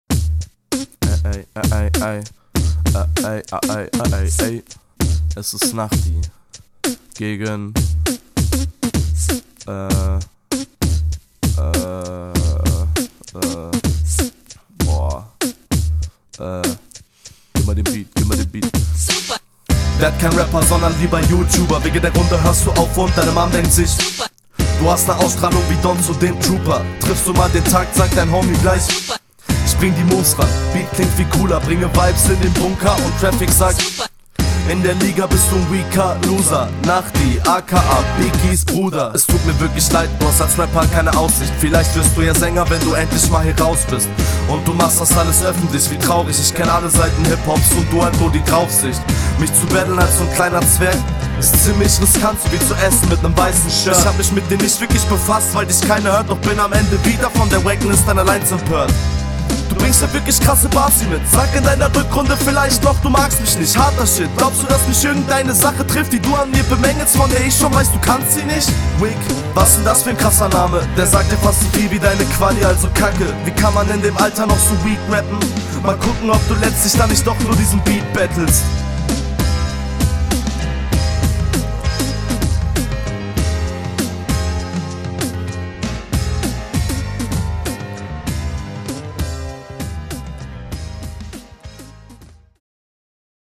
Erstmal super Einstieg, kommst richtig chillig auf dem Beat, die ersten Lines mit dem "super" …
Uff, also definitiv sehr starker und routinierter flow für die Entry, muss man vorab mal …
yoo, vibes cool und so, du rapst gut. es wirkt auf mich bisi so als …